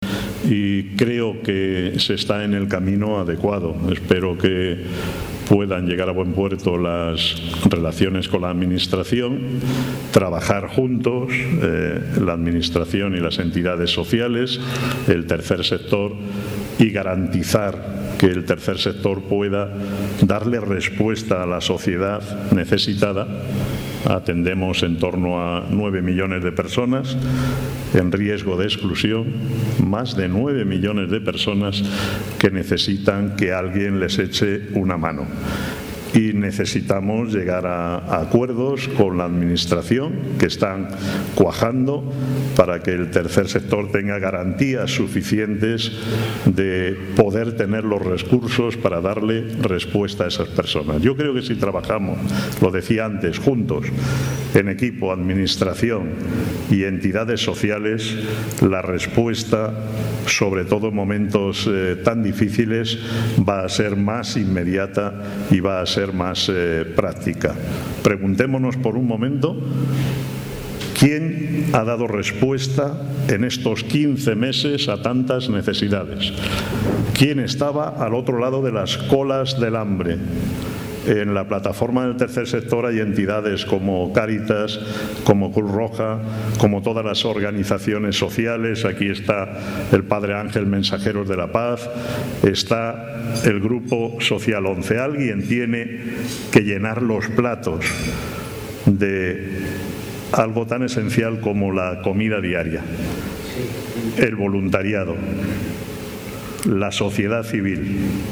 en el transcurso de un desayuno informativo organizado por Nueva Economía Forum